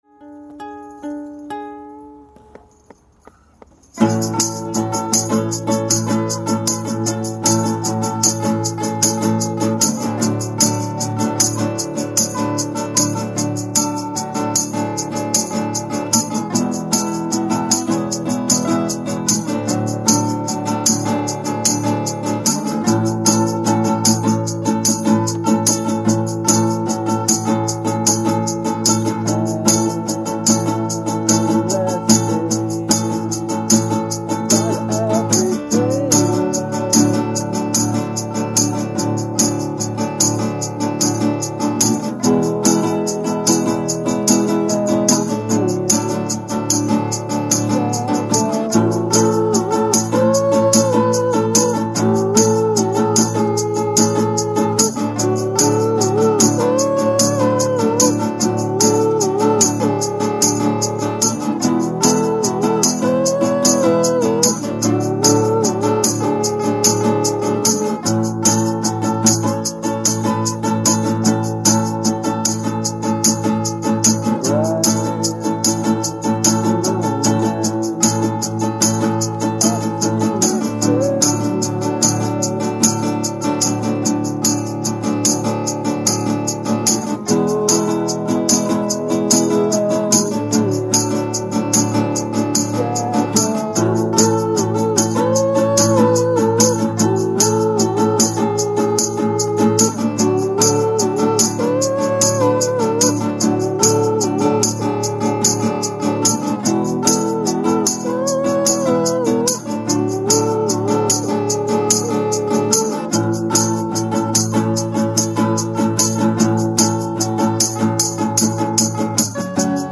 unplugged session